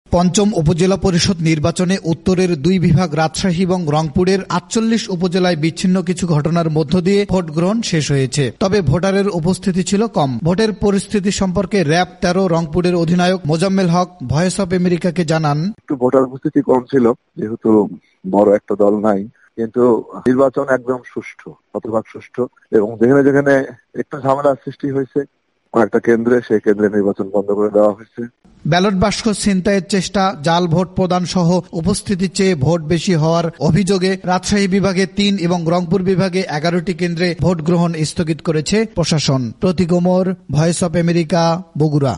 বগুড়া থেকে